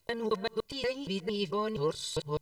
waveset transformation (reversal)
WavesetReversal_example.wav